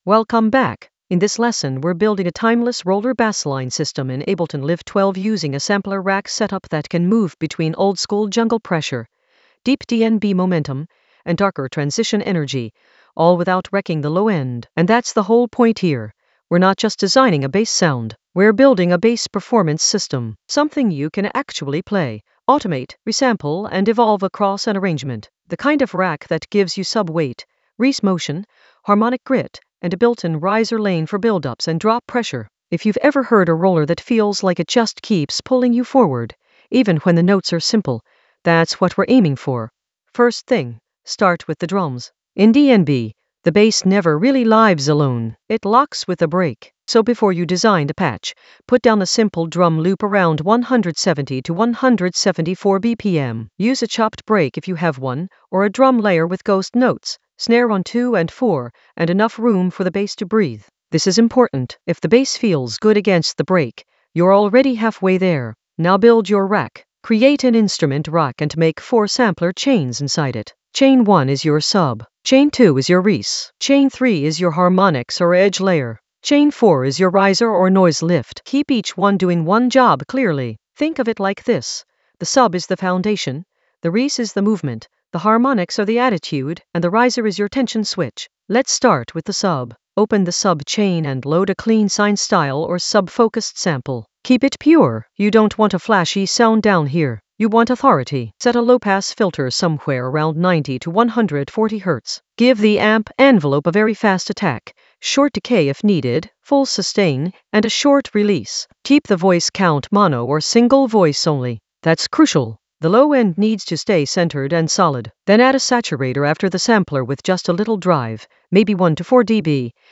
An AI-generated intermediate Ableton lesson focused on Bassline Theory Ableton Live 12 sampler rack system for timeless roller momentum for jungle oldskool DnB vibes in the Risers area of drum and bass production.
Narrated lesson audio
The voice track includes the tutorial plus extra teacher commentary.